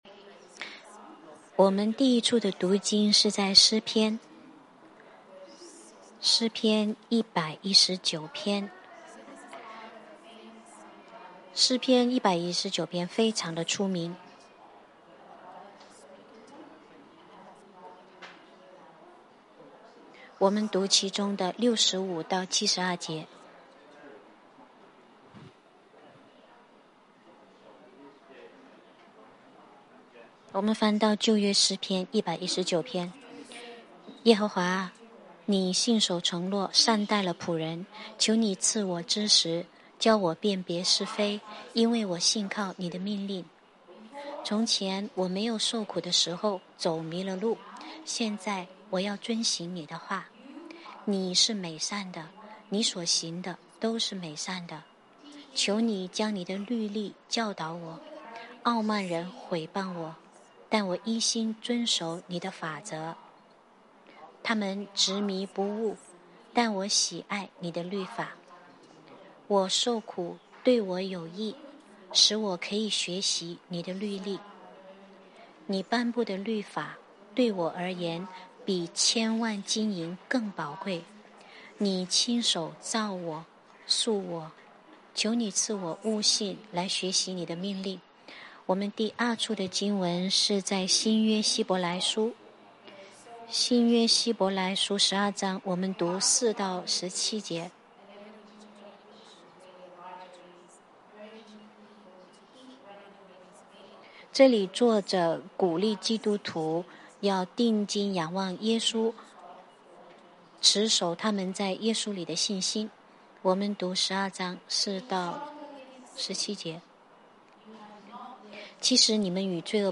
Sermons in Mandarin - The Lakes Church